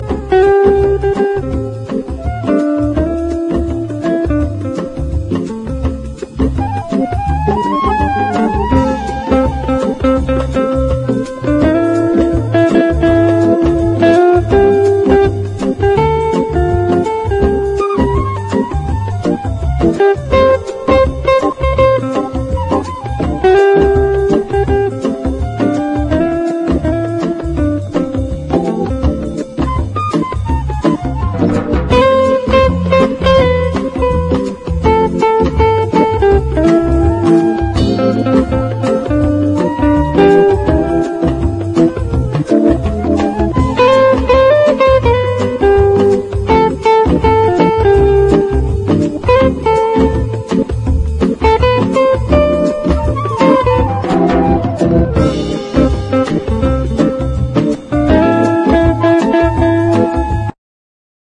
JAZZ / MAIN STREAM / VIBE
30年代録音の軽快なヴィンテージ・ヴァイブ・ジャズ！ヴォーカル曲も収録！